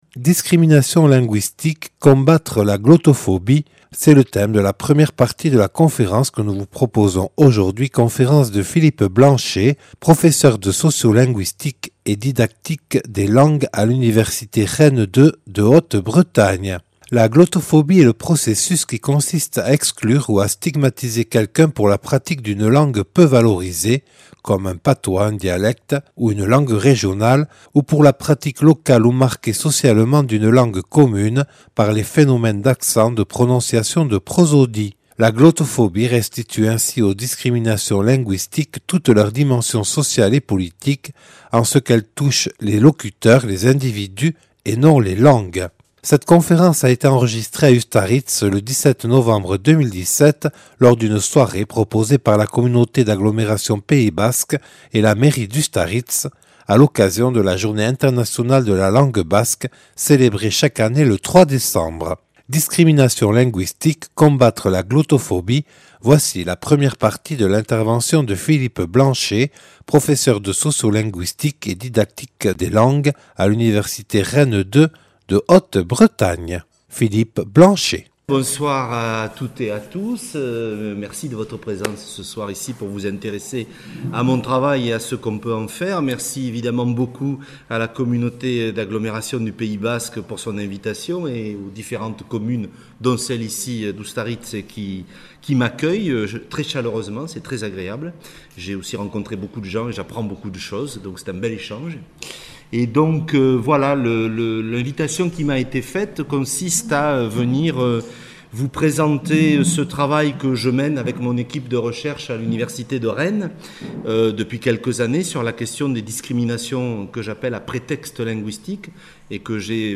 (Enregistré le 17/11/2017 à Ustaritz lors d’une soirée proposée par la Communauté d’Agglomération Pays Basque et la mairie d’Ustaritz à l’occasion de la Journée Internationale de la langue basque célébrée chaque année le 3 décembre).